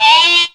OVERBLOW.wav